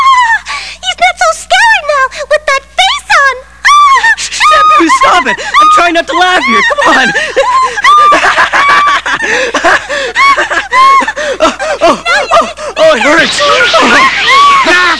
She laughs with Mousse
Laugh.wav